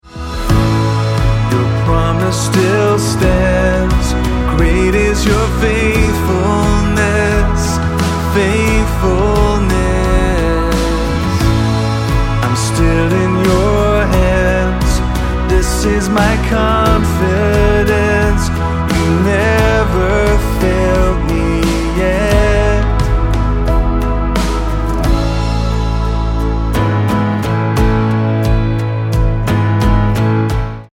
D